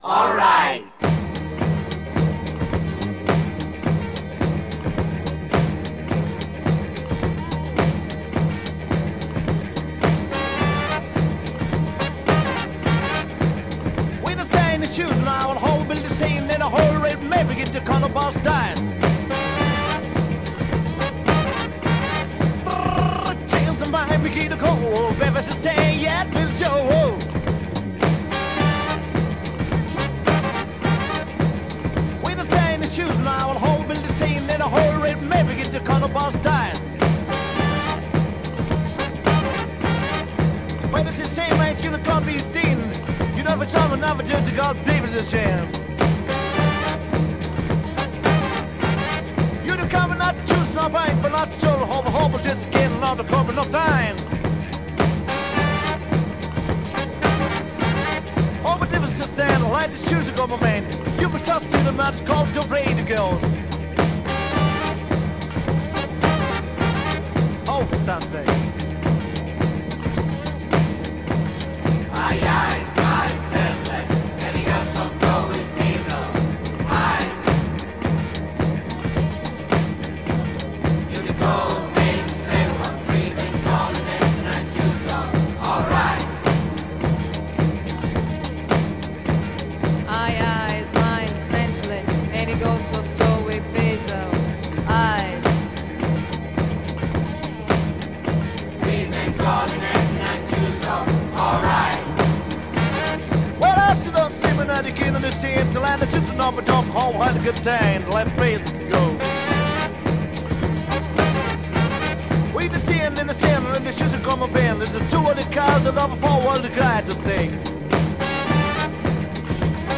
BPM107-107
Audio QualityCut From Video